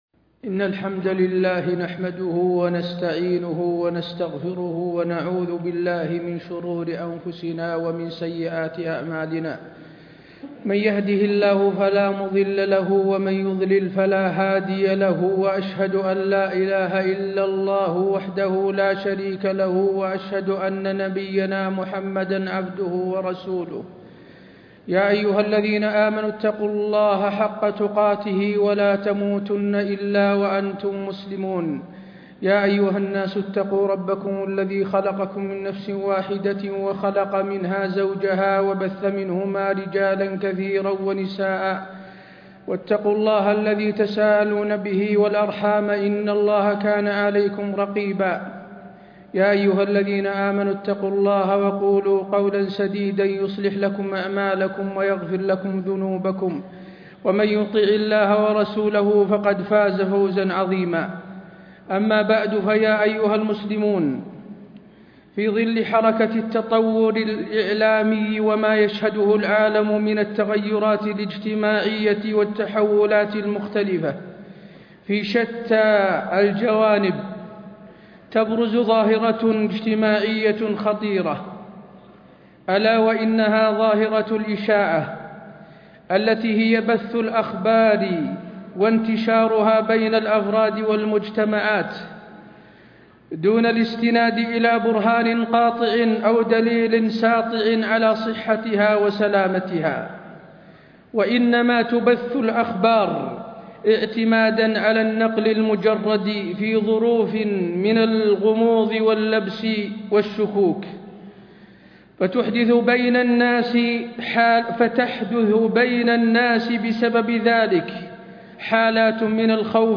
تاريخ النشر ٢٥ جمادى الآخرة ١٤٣٥ هـ المكان: المسجد النبوي الشيخ: فضيلة الشيخ د. حسين بن عبدالعزيز آل الشيخ فضيلة الشيخ د. حسين بن عبدالعزيز آل الشيخ الشائعات وآثارها على الأمة